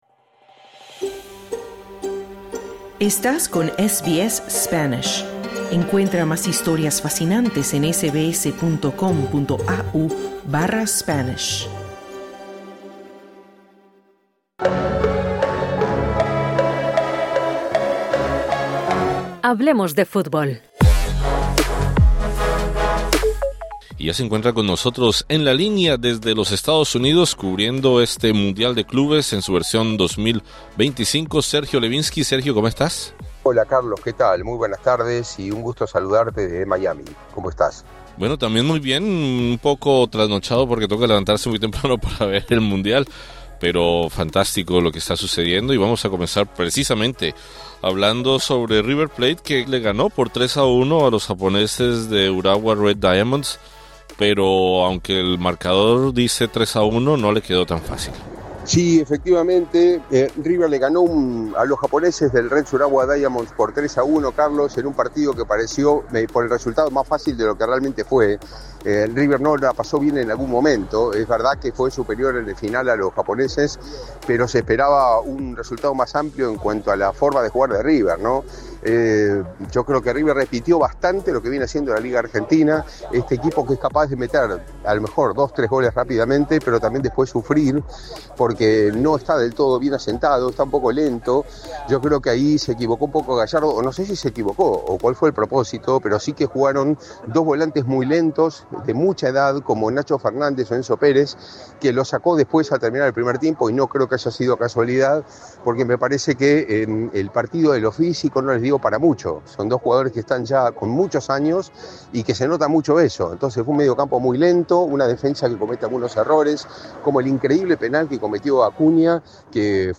River Plate de Argentina derrotó por 3 a 1 al Urawa Reds de Japón, mientras que el Borussia Dortmund alemán empató con el Fluminense de Brasil en el Mundial de Clubes de la FIFA. Escucha el reporte con nuestro enviado especial a Estados Unidos